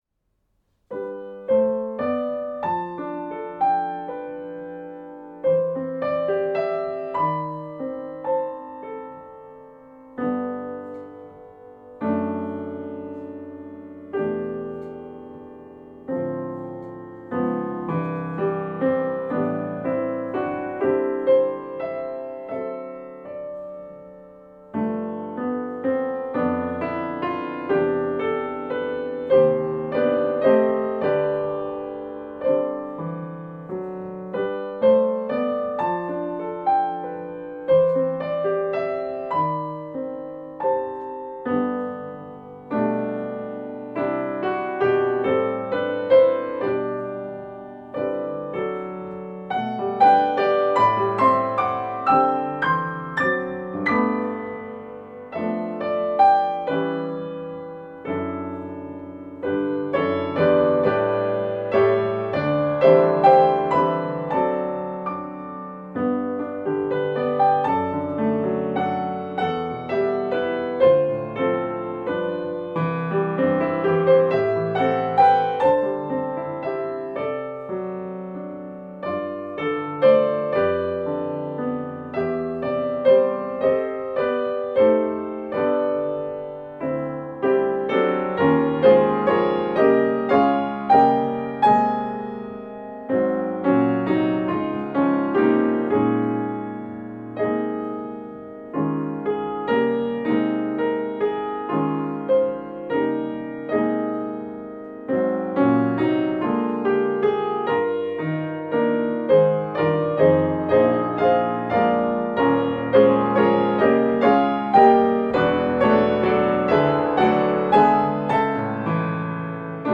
This recording is only the accompaniment.